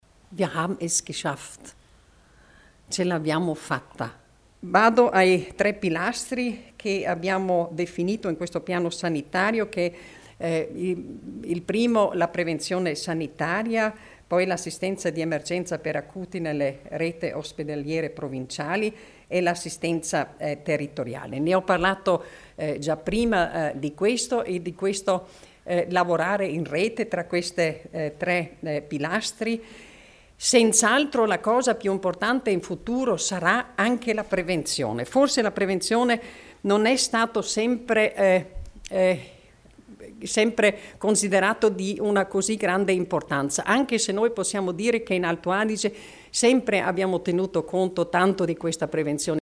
L'Assessore Stocker illustra il piano sanitario provinciale